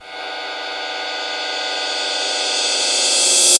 Rev Harsh Cym.wav